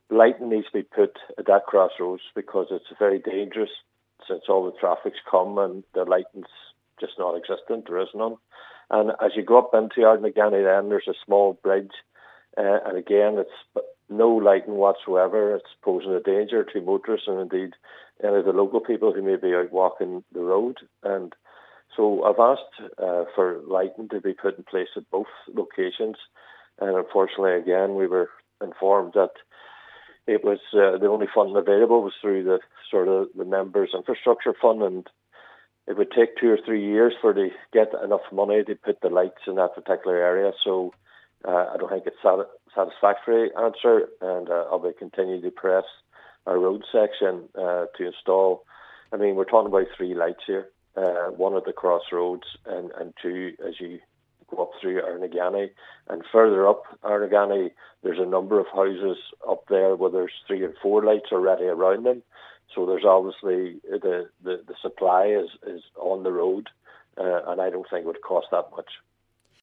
Mayor Councillor Gerry McMonagle has deemed this timeframe unacceptable: